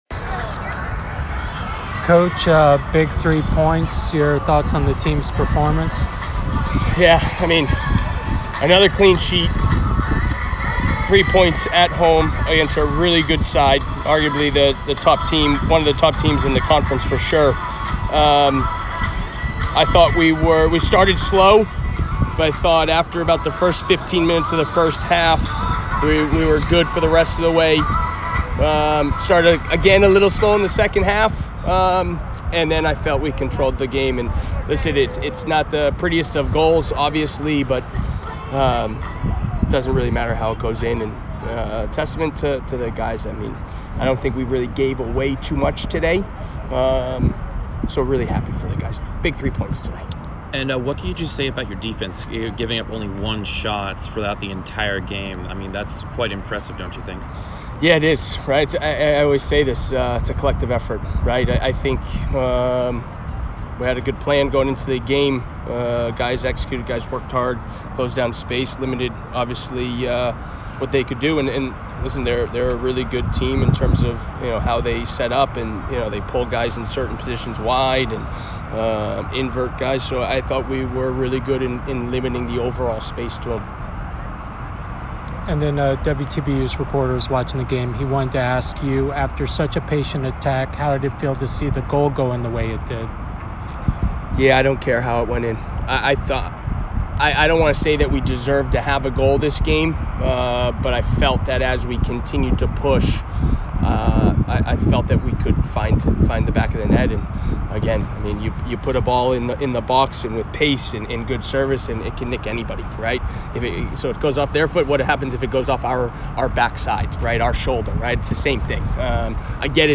American Postgame Interview